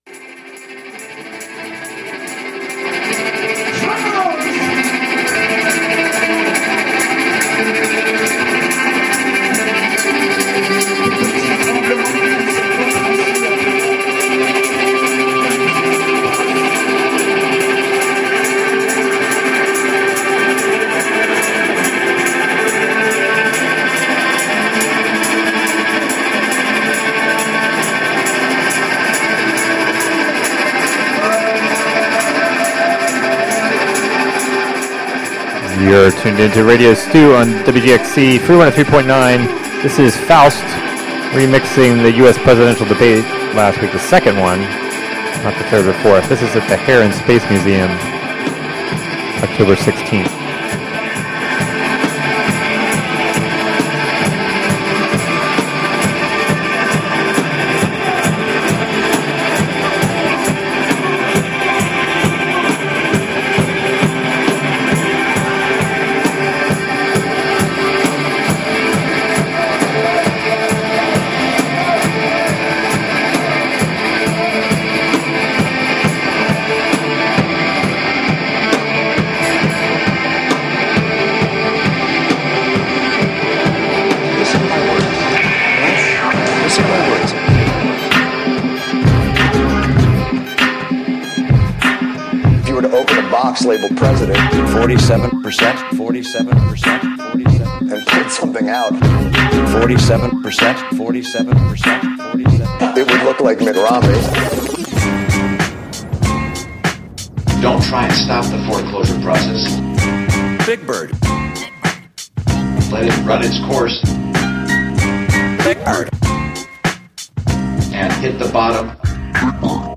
Tune in to Faust improvising to the second U.S. pr...